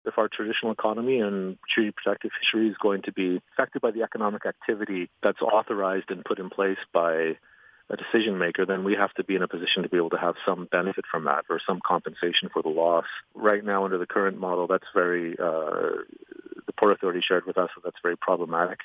Douglas White, Councillor and Chief Negotiator for the First Nation says they’ve been largely excluded from decision-making and the benefits of the harbour under both the Nanaimo Port Authority and the Nanaimo Harbour Commission….